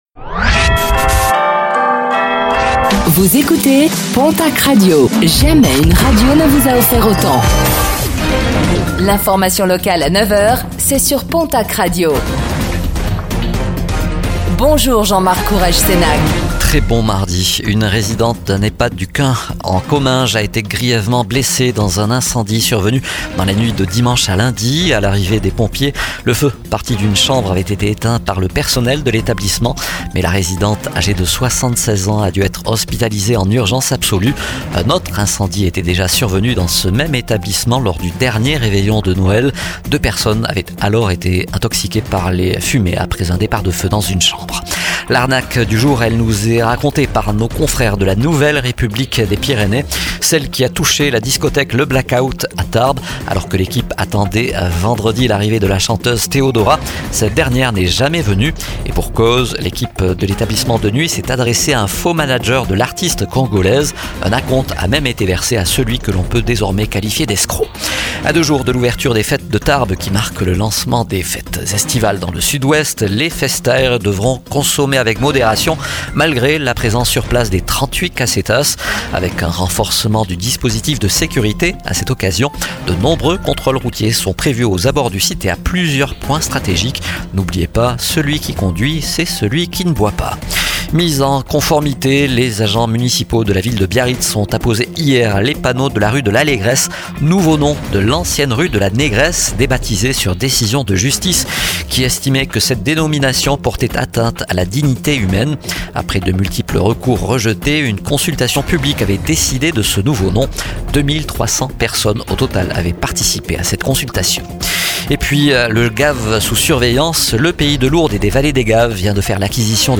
Infos | Mardi 17 juin 2025